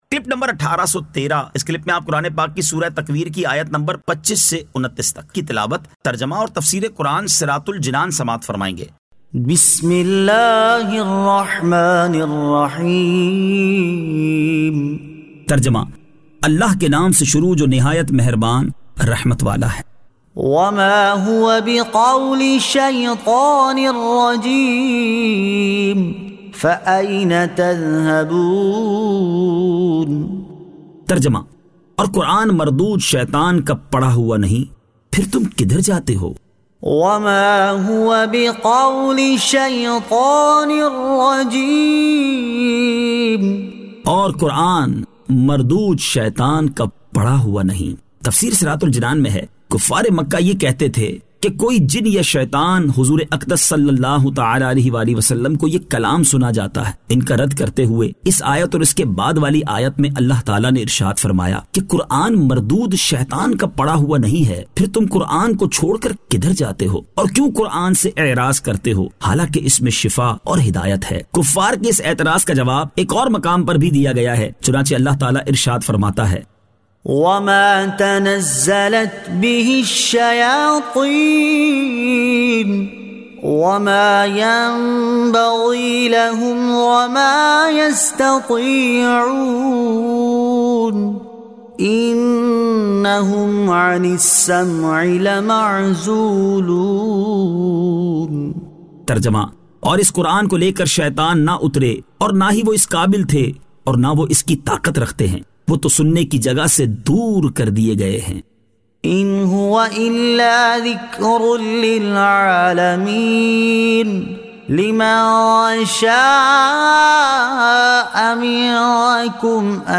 Surah At-Takwir 25 To 29 Tilawat , Tarjama , Tafseer